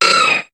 Cri de Galekid dans Pokémon HOME.